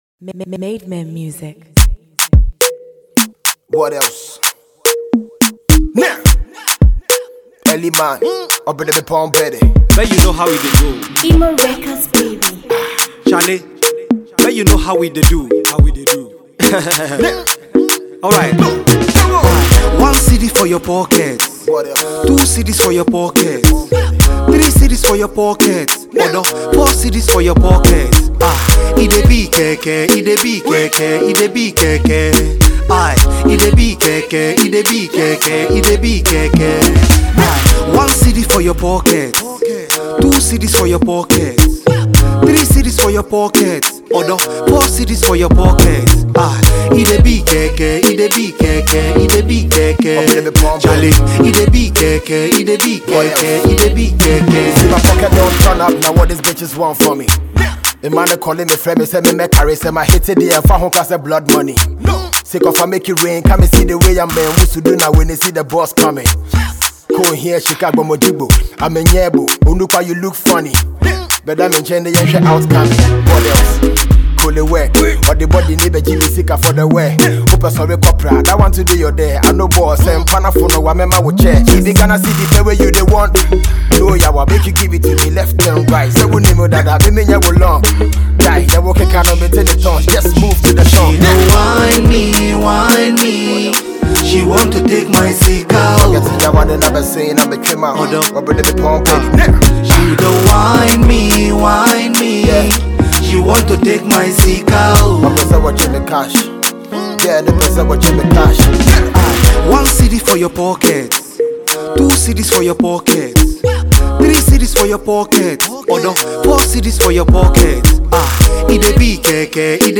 dance hall
Ghana’s rap finest